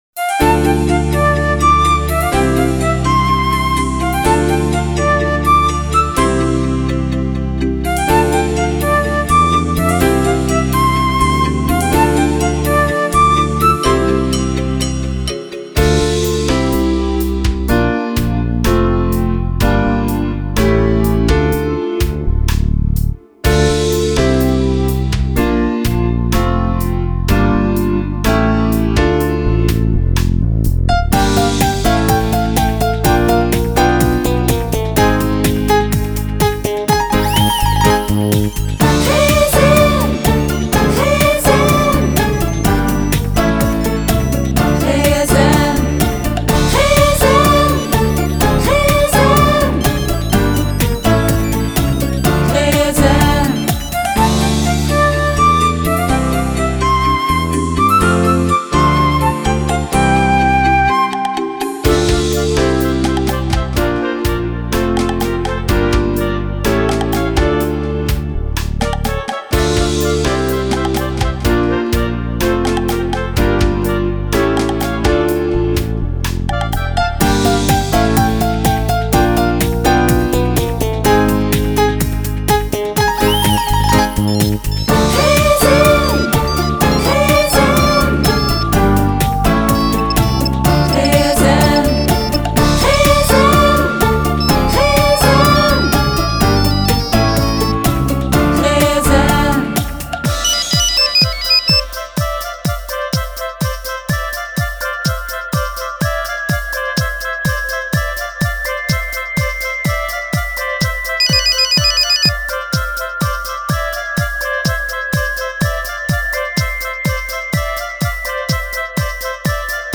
SchouderCom - Zonder zang
Lied-4-GSM-instrumentaal-.mp3